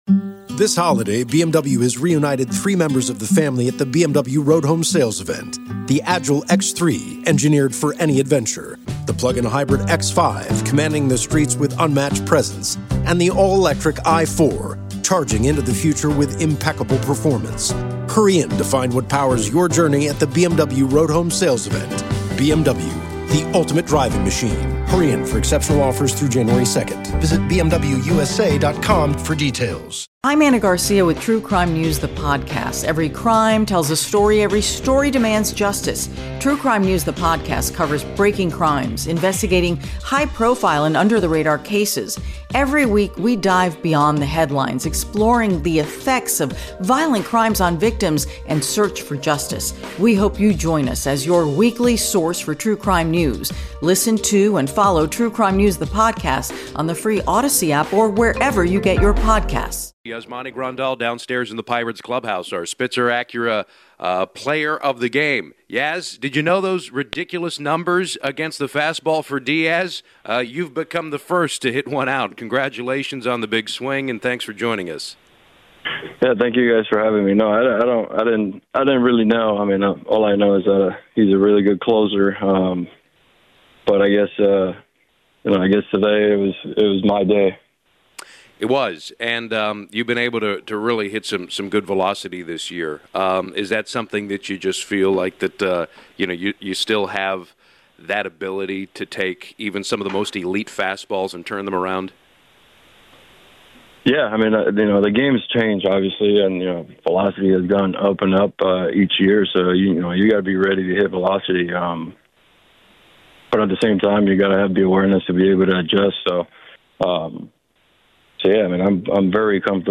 Pirates catcher Yasmani Grandal joins postgame, as he goes over his clutch at-bat against Alexis Diaz, and the vibe with the Pirates.